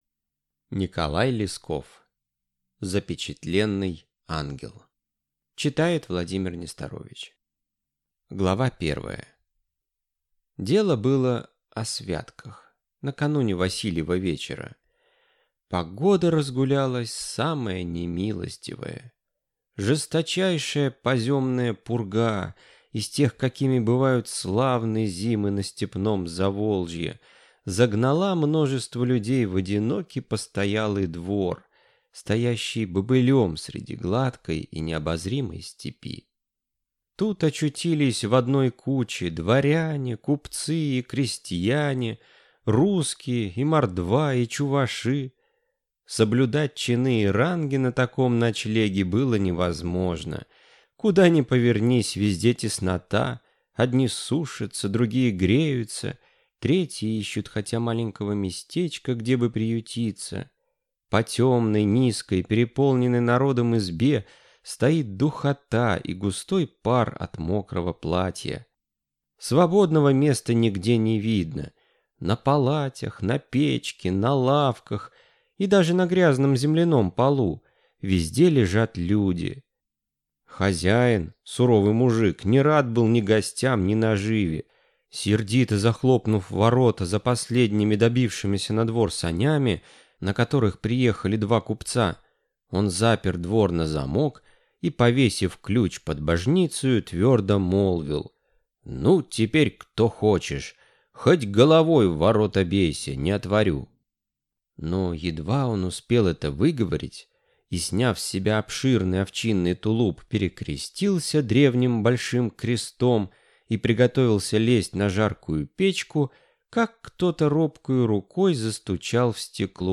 Аудиокнига Запечатленный ангел | Библиотека аудиокниг